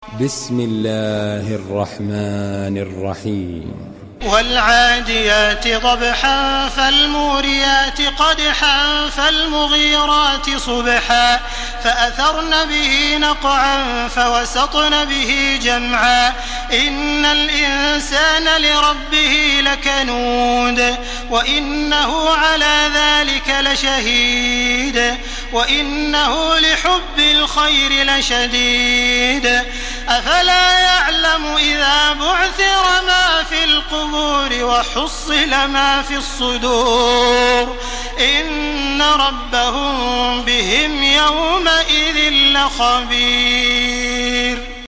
Surah Al-Adiyat MP3 in the Voice of Makkah Taraweeh 1431 in Hafs Narration
Listen and download the full recitation in MP3 format via direct and fast links in multiple qualities to your mobile phone.
Murattal